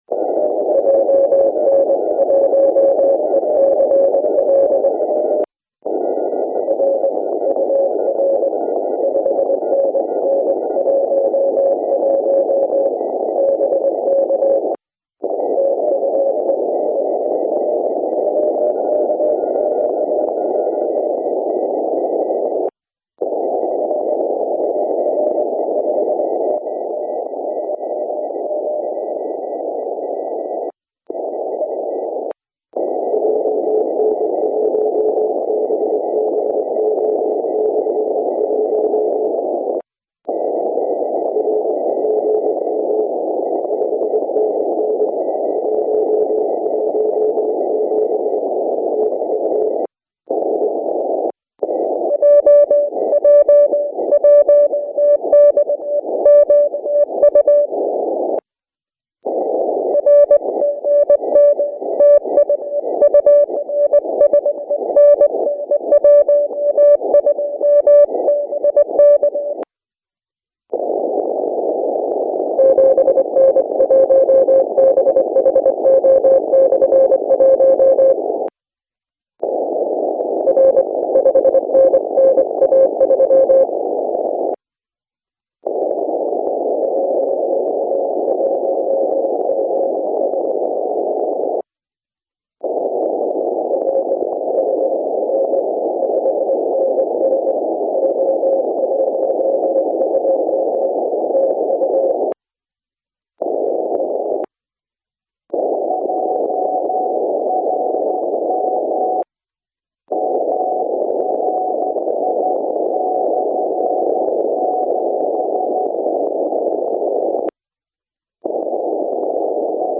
From 1032 to 1041 LMT, May 03, 2014 at the Tokyo Contest on 50 MHz CW. The left speaker represents stacked 8-element Yagis directed to the West (Tokyo and JA2-JA6 area)while the right is a five-element Yagi toward the North (JA0, JA7 and JA8).
One can see fast QSB (about 1 cycle/sec) on his waveform.